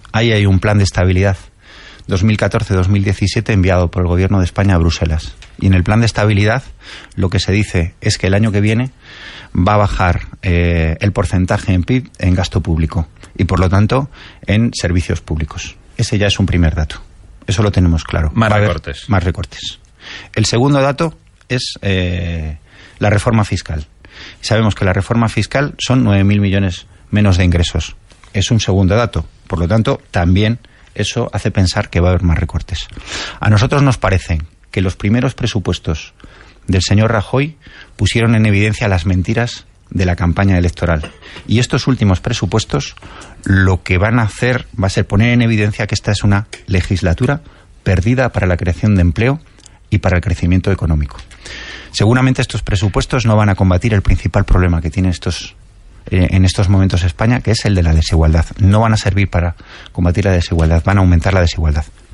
Fragmento de la entrevista de Antonio Hernando en Las Mañanas de RNE 26/09/2015